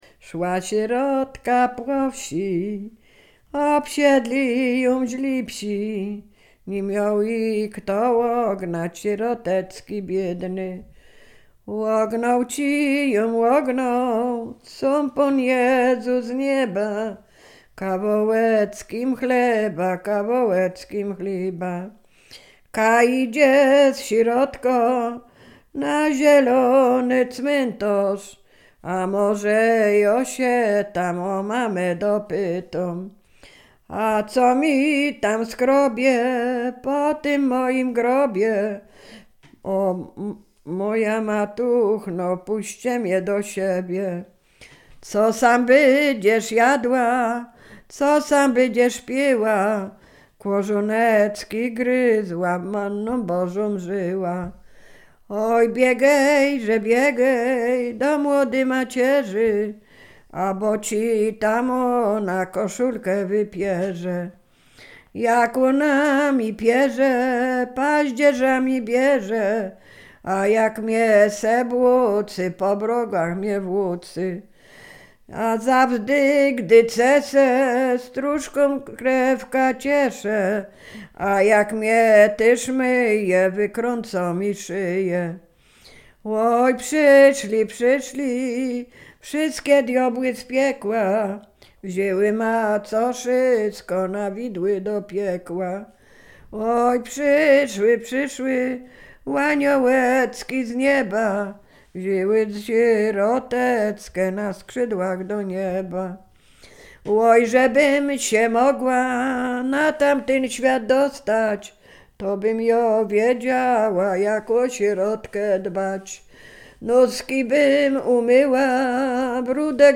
Sieradzkie
Ballada
ballady dziadowskie